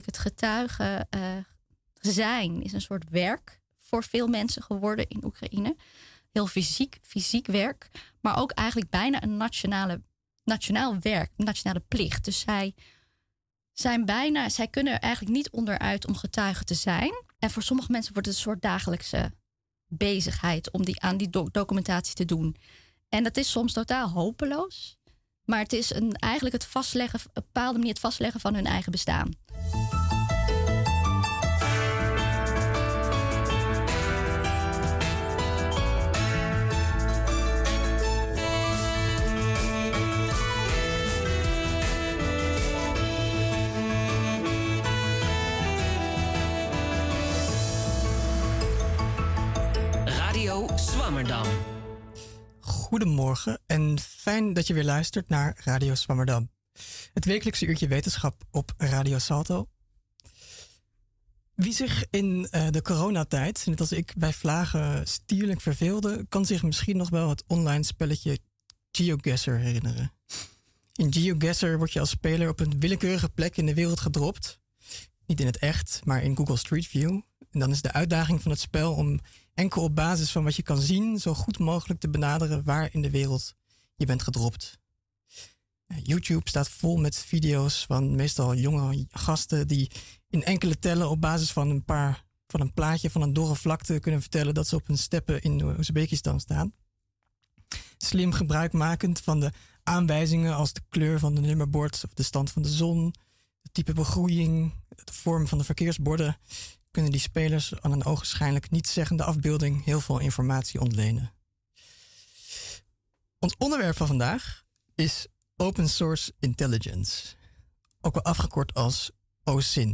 In onze knusse studio in Pakhuis de Zwijger schuiven wetenschappers aan om hun onderzoek uitgebreid en toegankelijk toe te lichten. Het gesprek is iedere zondagochtend van 11:00 tot 12:00 te horen op Radio Salto.